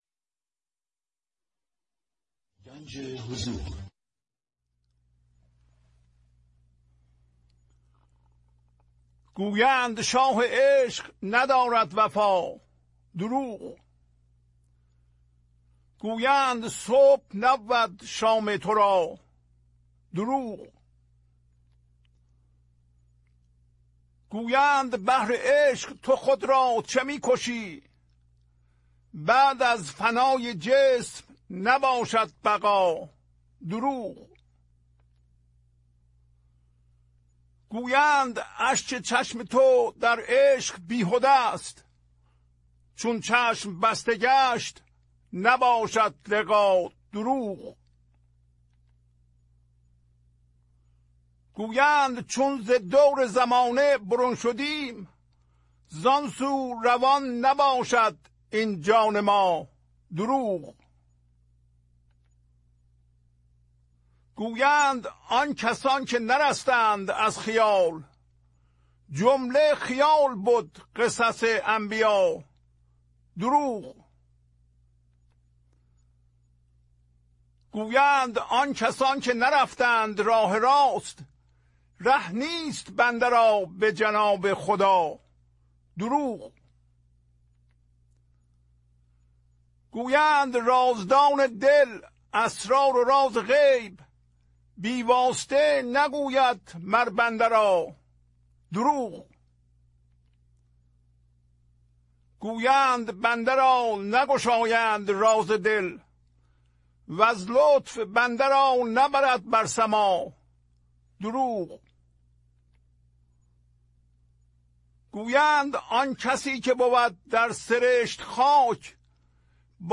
خوانش تمام ابیات این برنامه - فایل صوتی
1018-Poems-Voice.mp3